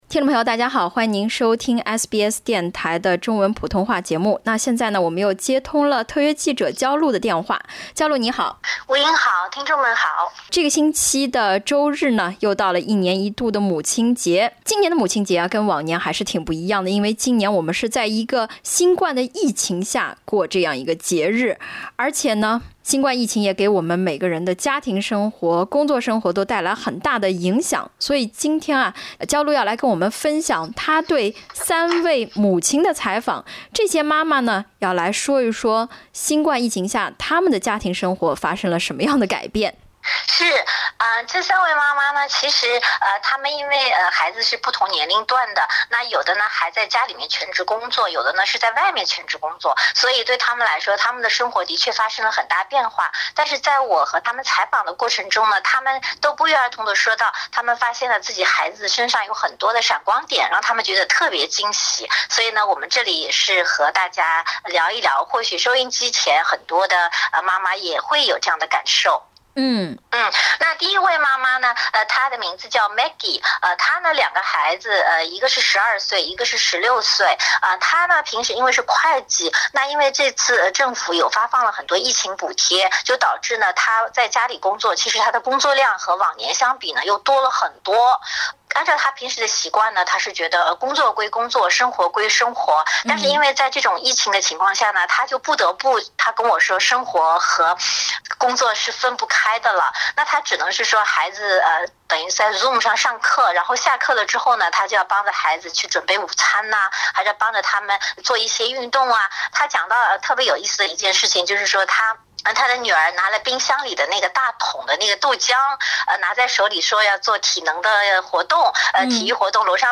她寀訪了三位在澳洲的華人媽媽，三位媽媽都異口同聲地表示，疫情給她們的生活帶來了影響，也讓她們更多地髮現子女或配偶身上一些令她們驚喜的潛質。